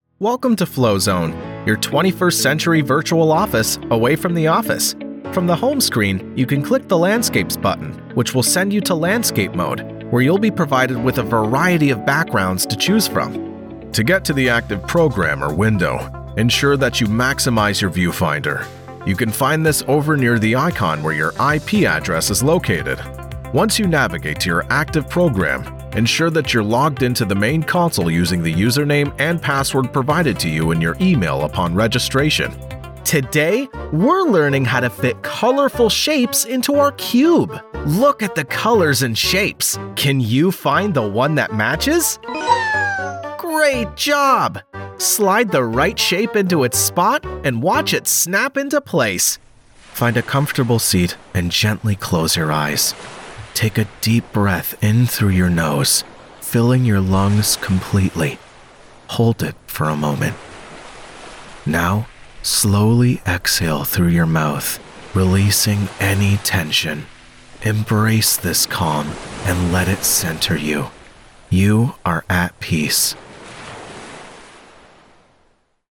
Narration
All recordings are done in my professional sound-treated vocal booth.